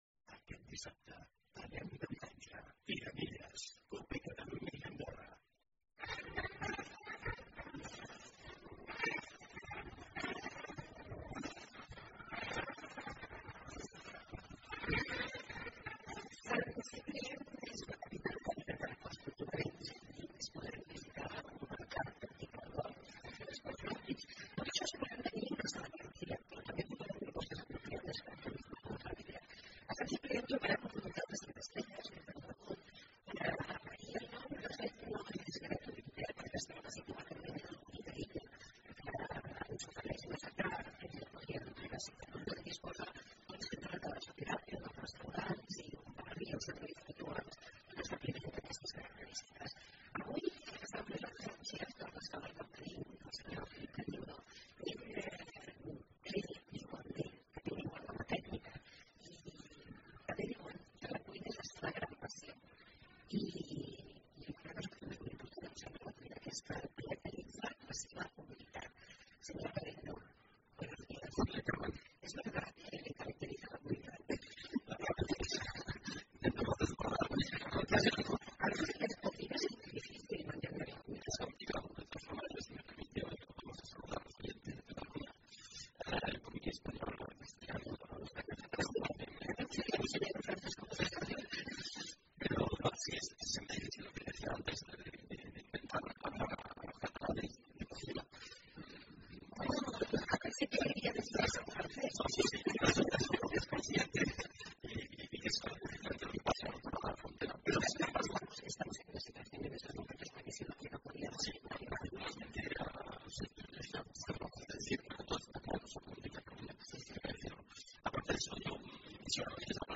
Programa especial desde Perpiñan (Segunda hora,segunda parte)